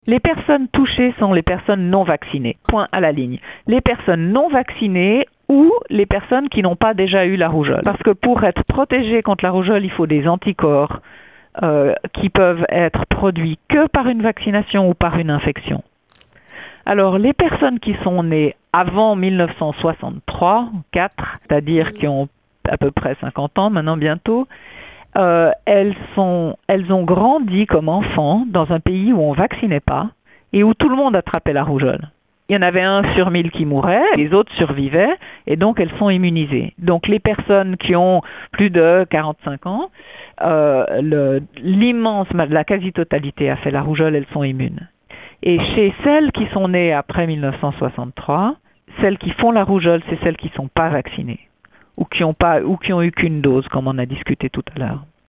Claire-Anne Siegrist, présidente de la Commission fédérale pour les vaccinations